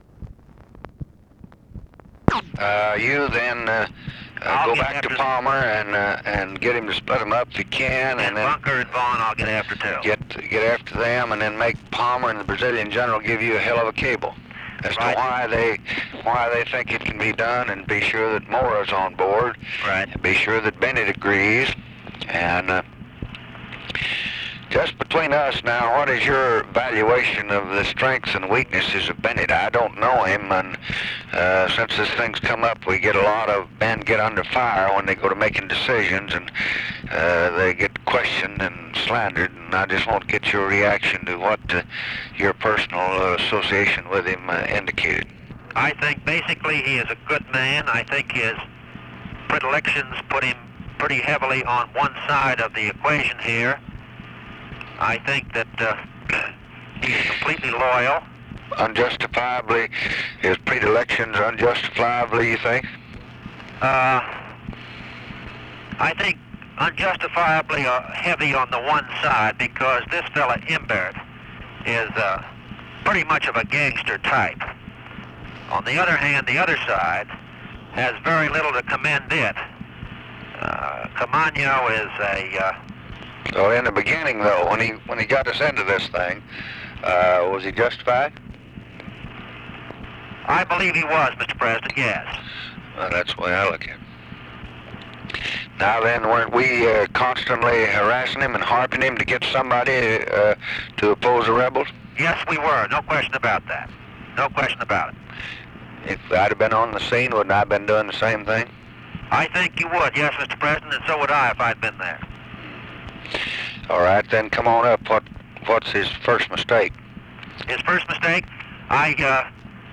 Conversation with CYRUS VANCE, May 31, 1965
Secret White House Tapes